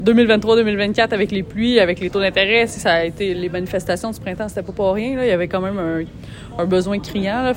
En conférence de presse mercredi